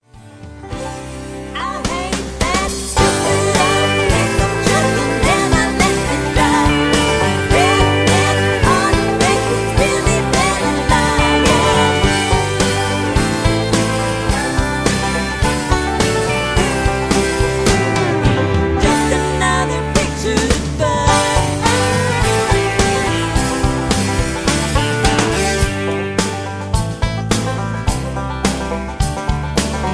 (Version-4, Key-G) Karaoke MP3 Backing Tracks